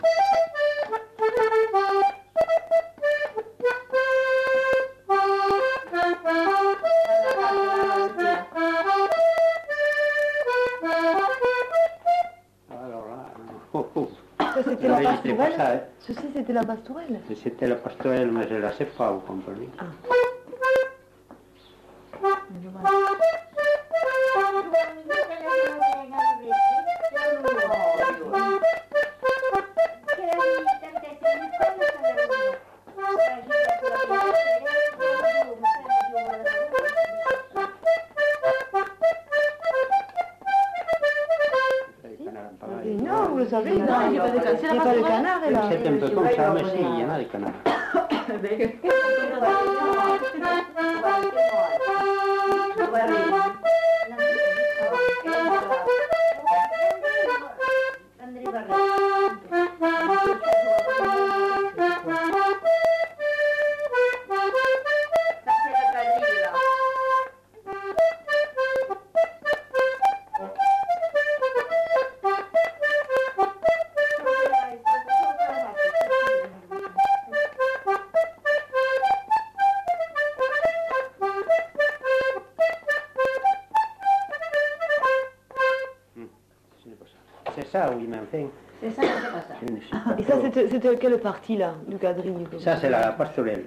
Genre : morceau instrumental
Instrument de musique : accordéon diatonique
Danse : quadrille